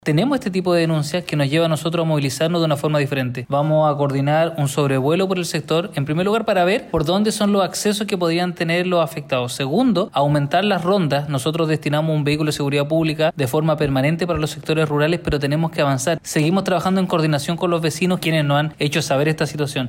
Respecto a los hechos de robo denunciados, el alcalde Luciano Valenzuela confirmó que coordinarán un sobrevuelo por el sector para visualizar los accesos que podían tener los afectados.